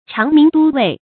长鸣都尉 cháng míng dōu wèi 成语解释 鸡的别名。